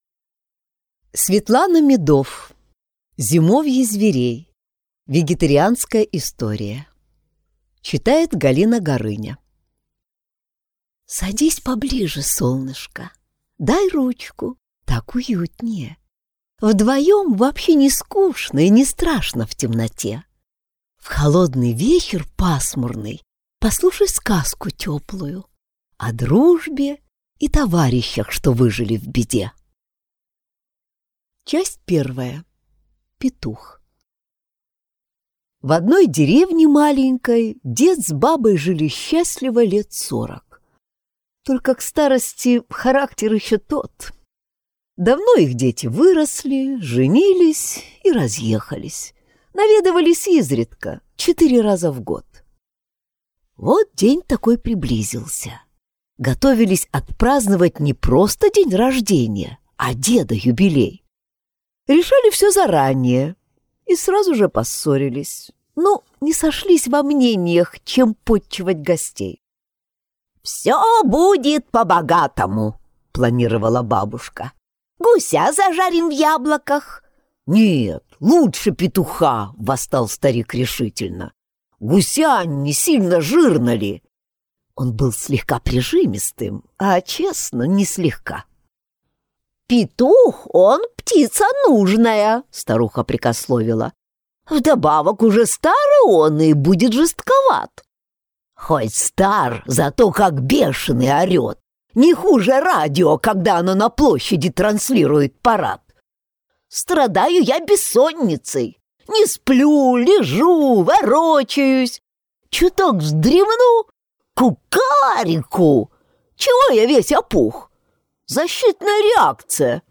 Аудиокнига Зимовье зверей. Вегетарианская история | Библиотека аудиокниг